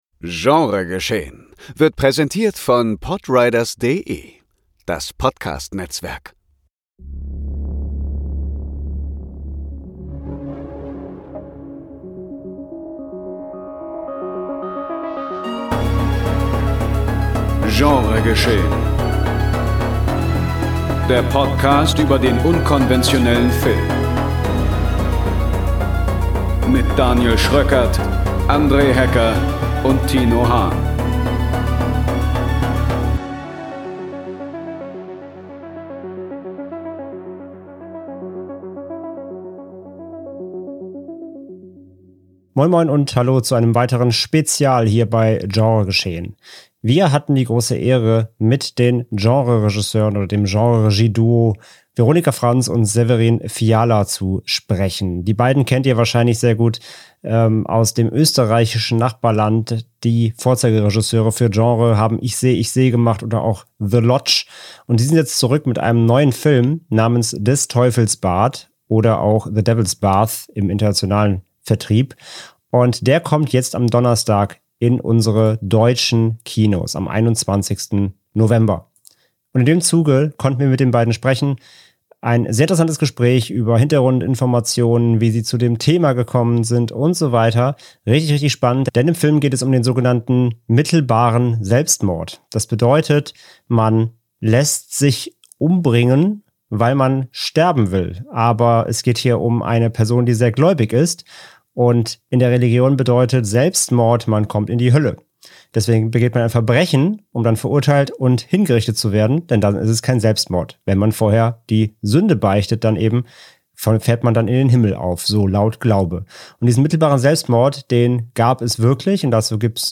Spezial - Interview mit Veronika Franz & Severin Fiala (Des Teufels Bad / The Devil's Bath) ~ Genre Geschehen Podcast